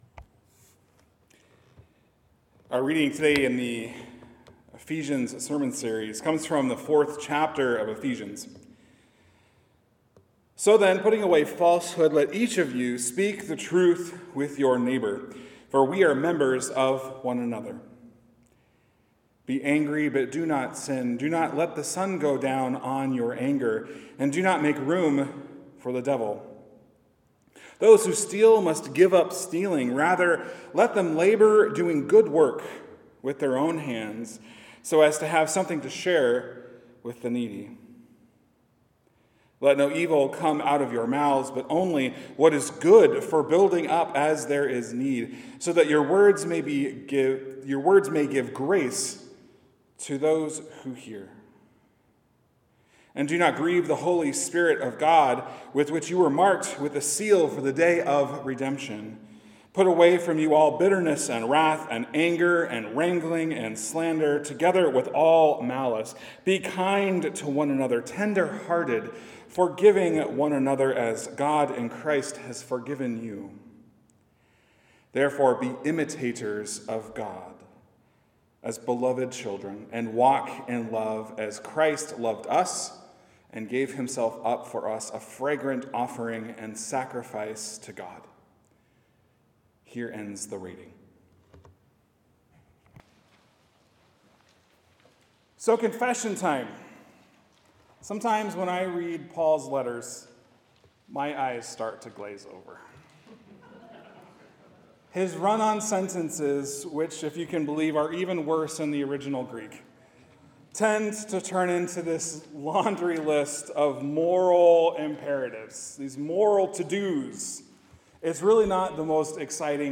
Sermons | Joy Lutheran Church